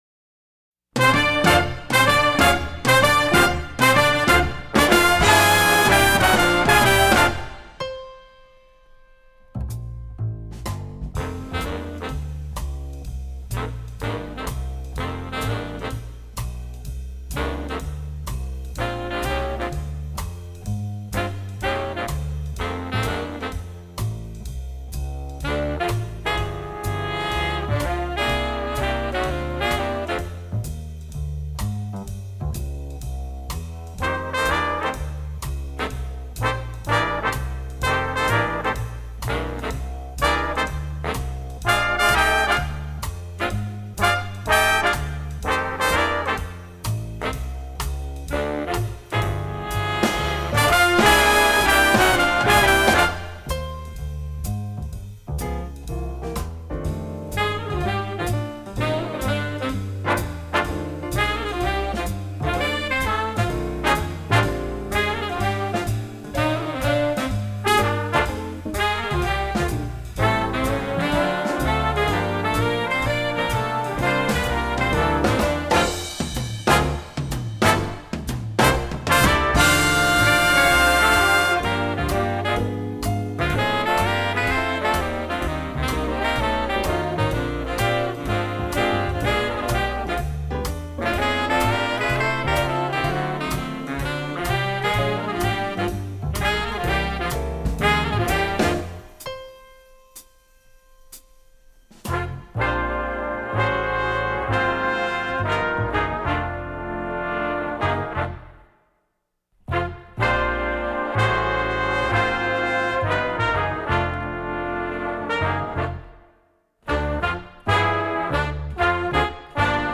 Jazz Band